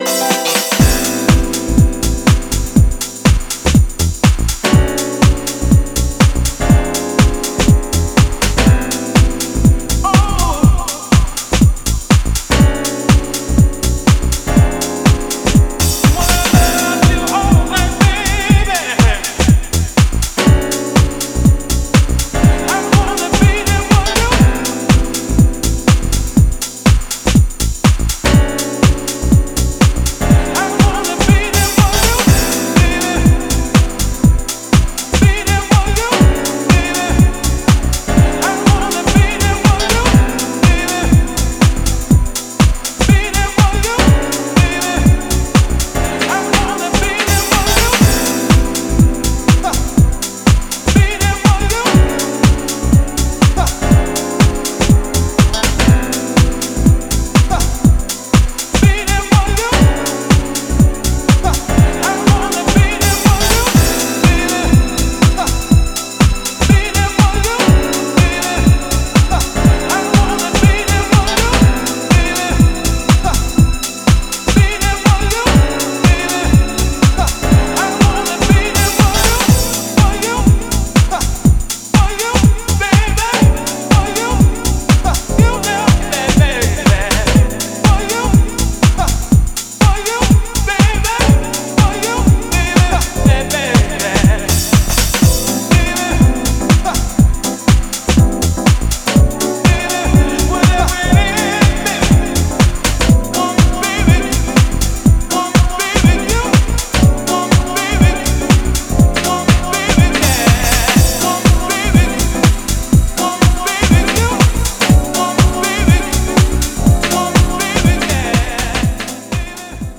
絢爛なピアノとソウルフルなヴォイス・サンプルがウォームでオーセンティックな魅力を放つディープ・ハウス